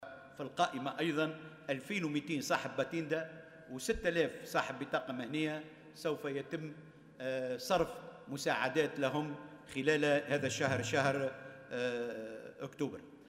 أكد وزير الشؤون الاجتماعية محمد الطرابلسي، اليوم الجمعة خلال الجلسة العامة المنعقد بالبرلمان، أنه سيتم خلال الشهر الحالي، صرف مساعدات مالية استثنائية لفائدة 2200 من أصحاب الباتيندا و 6000 من أصحاب البطاقات المهنية، المتضرّرين من جائحة "كورونا".